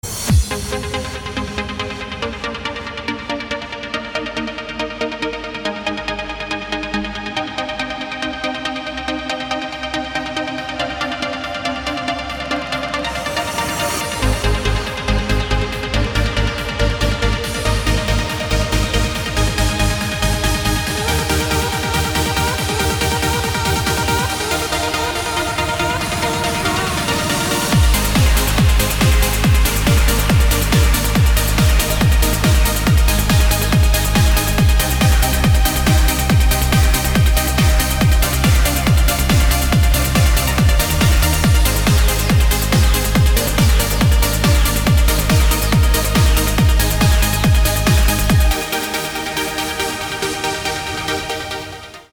• Качество: 320, Stereo
dance
Electronic
без слов
Trance